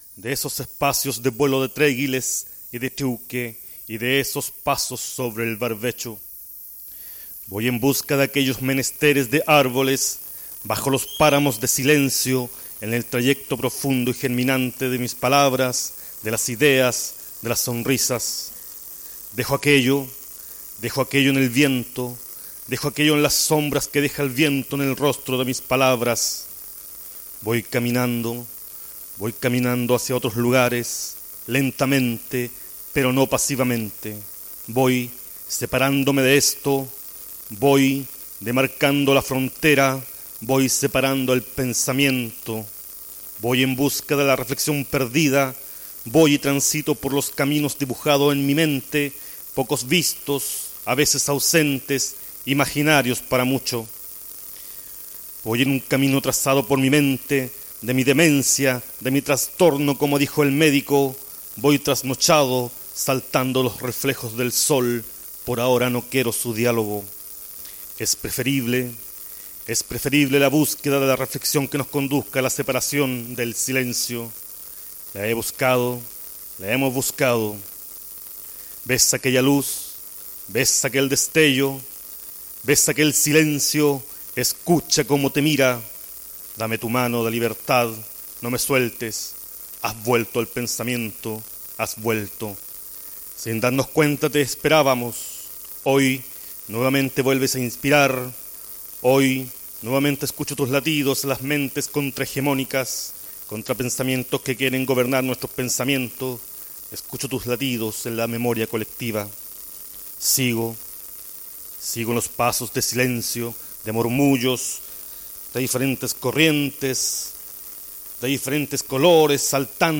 Poesía